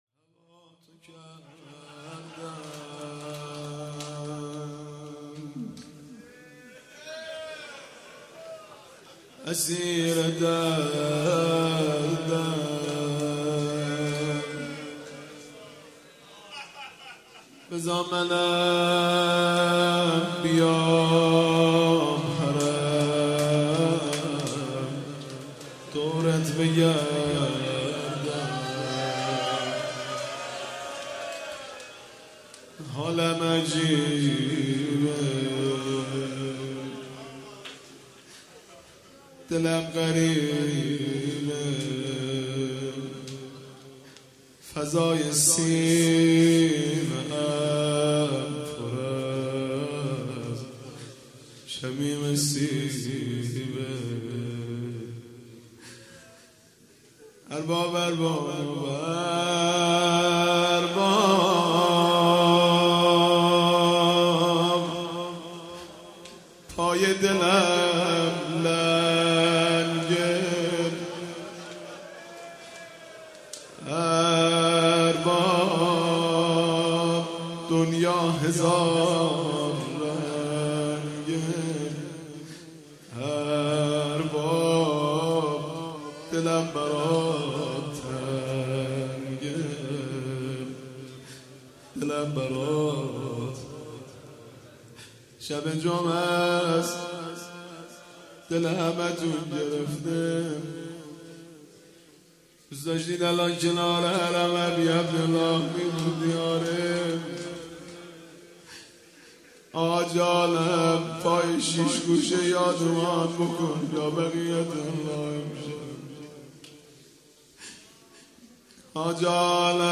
مداحی جدید حاج مهدی رسولی شب چهارم محرم 97 هیئت‌ ثارالله‌ زنجان
yeknet.ir_-monajat_-_hajmahdirasuli_-_shab4moharam1440_-_sarallahzanjan.mp3